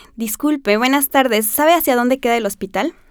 A continuación te invito a que escuches algunas variedades del español. Todas las colaboradoras son mujeres, nacieron en el país que están representando en este ejemplo, tienen una edad promedio entre 25 y 35 años, tienen estudios universitarios, son de clase media y no han vivido por más de un año en un país diferente al suyo.
Además, fueron grabadas por la autora y a todas se les propuso la misma tarea de completamiento discursivo (DCT), es decir, debían imaginar que estaban en la siguiente situación y responder espontáneamente, como lo harían en su vida cotidiana: “Se encuentra en una ciudad que no conoce y necesita saber dónde queda el hospital. Pasa un señor por la calle, ¿cómo se lo preguntaría?”.
México
M--xico.wav